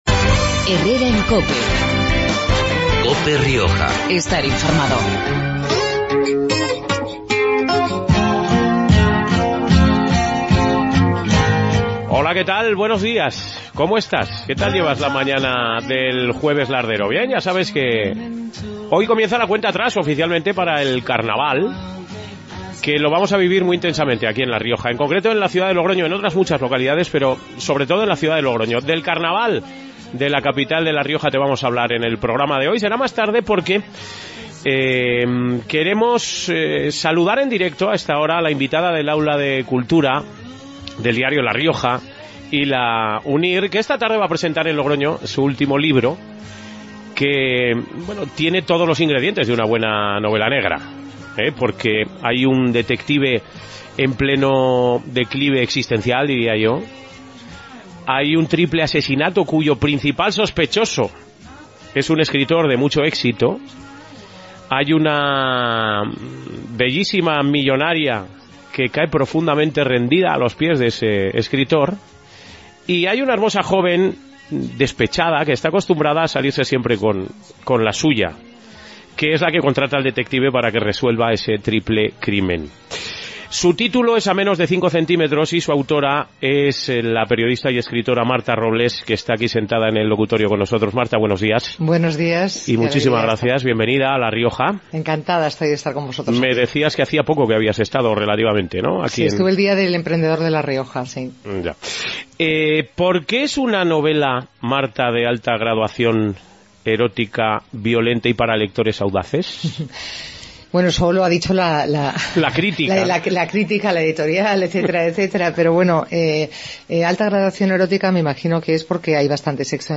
AUDIO: Programa regional de actualidad, entrevistas y entretenimiento.
Hoy entrevistamos a la periodista y escritora Marta Robles, que presenta en Logroño su última novela 'A menos de cinco centímetros'.